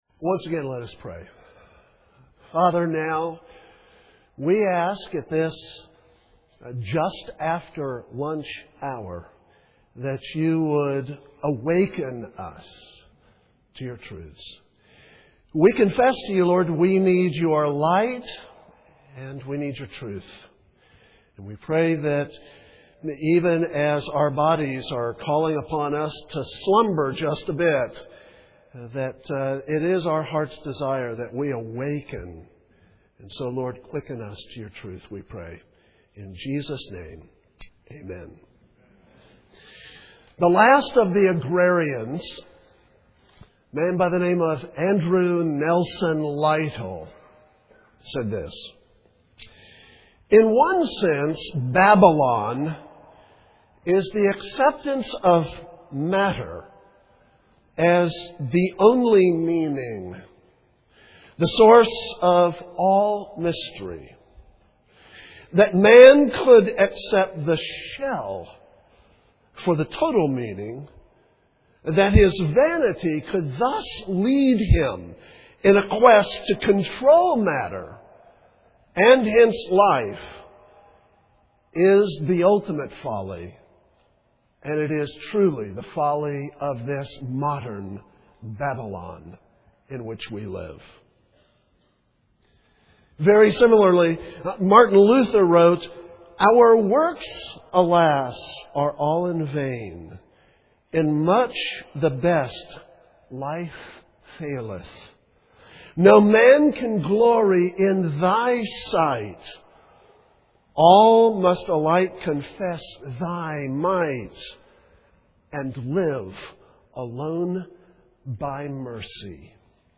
2007 Plenary Talk | 0:46:11 | Culture & Faith
Jan 31, 2019 | Conference Talks, Culture & Faith, Library, Media_Audio, Plenary Talk | 0 comments